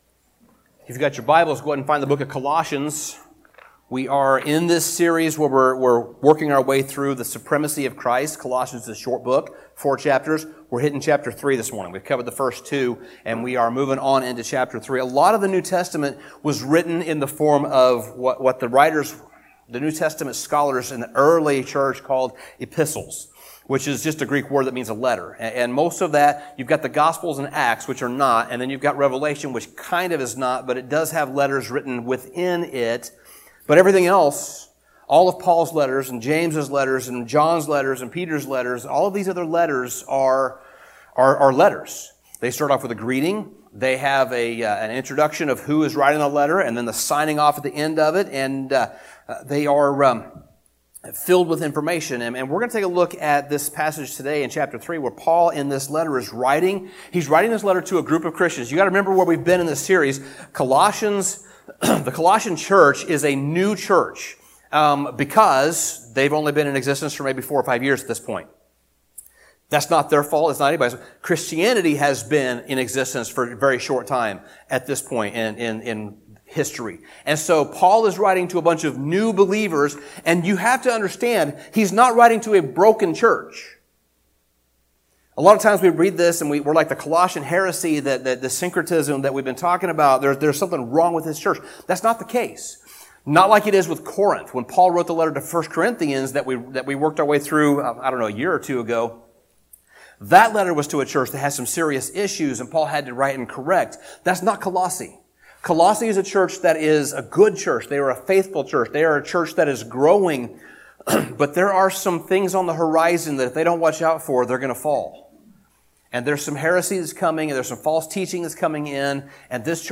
Sermon Summary In Colossians 3, Paul shifts from implication to application.